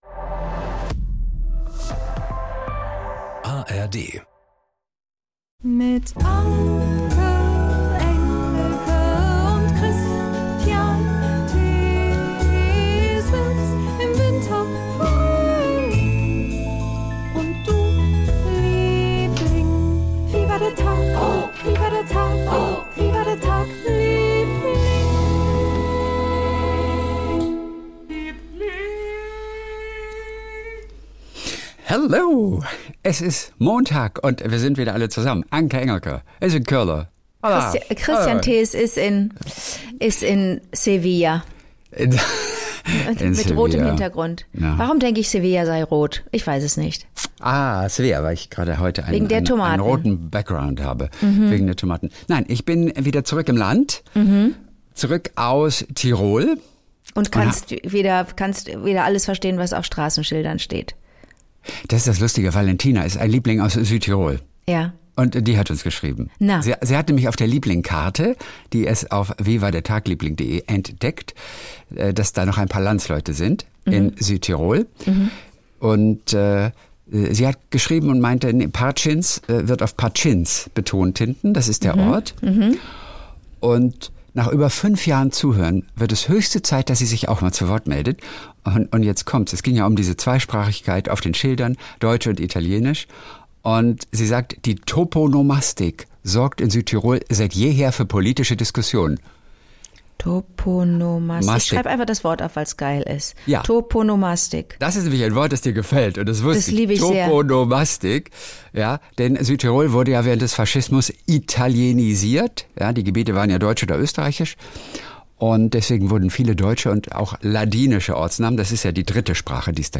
Die kleinen Geschichtchen des Tages mit Anke Engelke und SWR3-Moderator Kristian Thees.